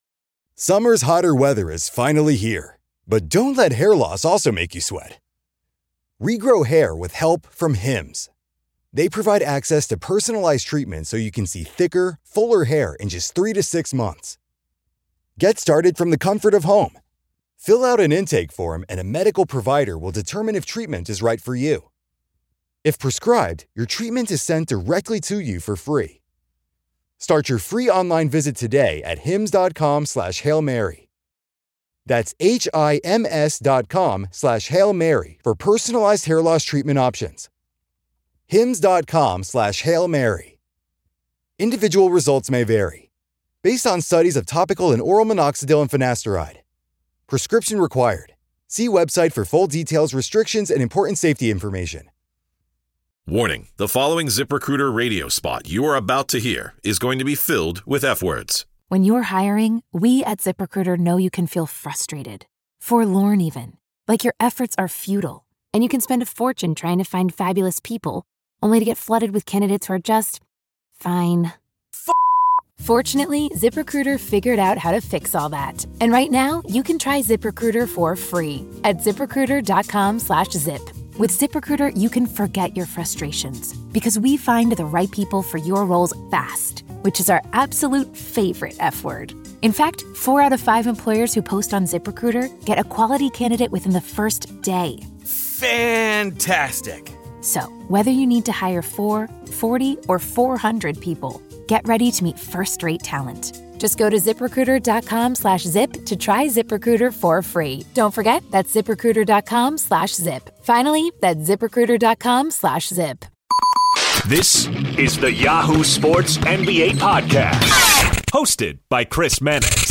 Brian Scalabrine The Crossover NBA Show SI NBA Basketball, Sports 4.6 • 641 Ratings 🗓 14 May 2018 ⏱ 45 minutes 🔗 Recording | iTunes | RSS 🧾 Download transcript Summary Joining Chris Mannix of Yahoo Sports this week is Brian Scalabrine, former NBA forward and NBA Insider for Yahoo and NBC Sports Boston.